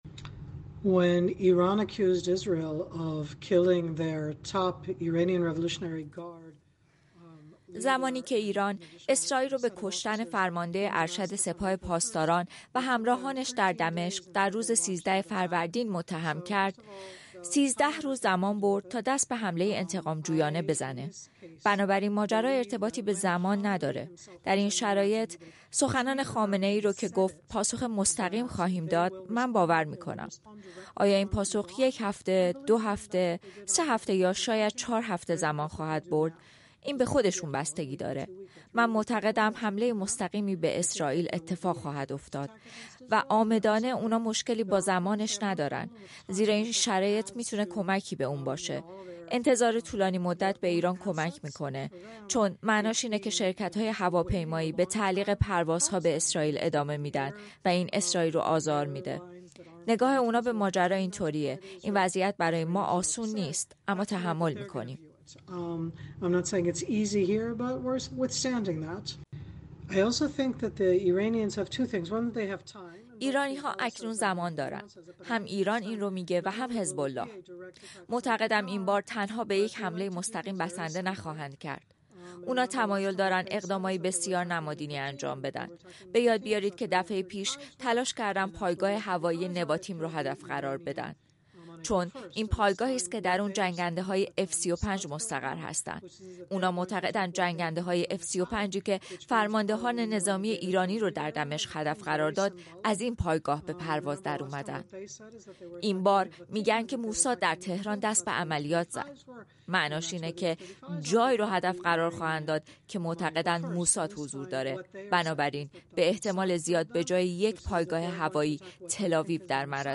در گفتگو با رادیو فردا